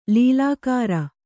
♪ līlākāra